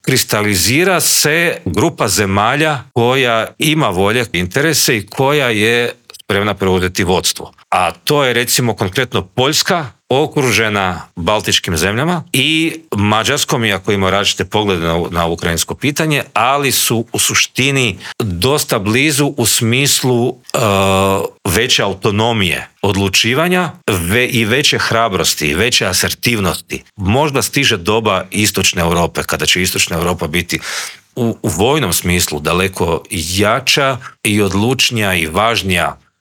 Intervjua Media Servisa